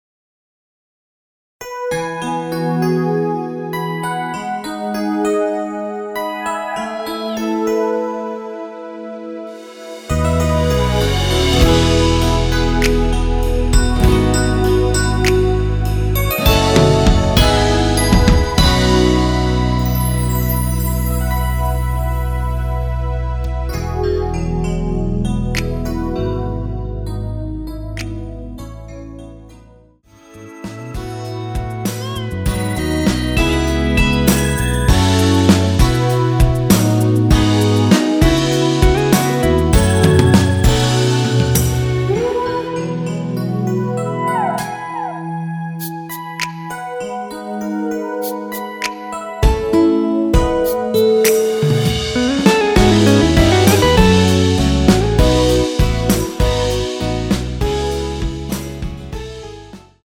원키에서(+2)올린 2절 삭제한 MR입니다.
앞부분30초, 뒷부분30초씩 편집해서 올려 드리고 있습니다.
중간에 음이 끈어지고 다시 나오는 이유는